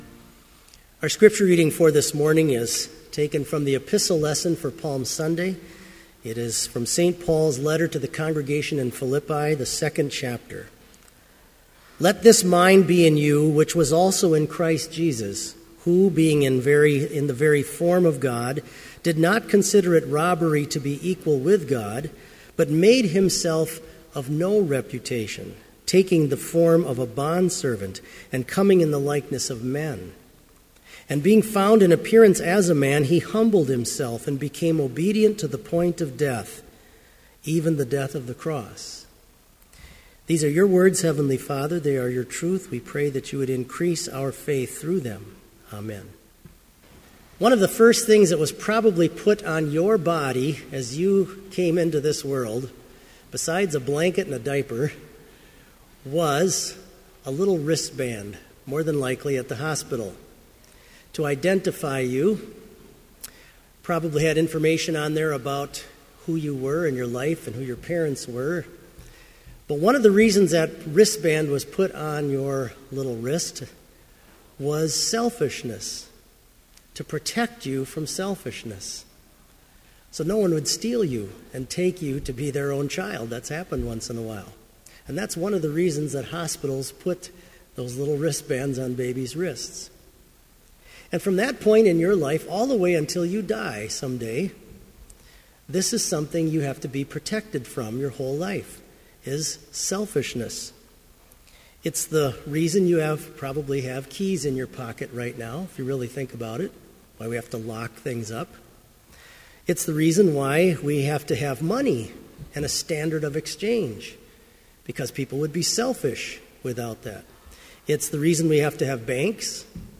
Chapel service on March 28, 2015, at Bethany Chapel in Mankato, MN,
Complete service audio for Chapel - March 28, 2015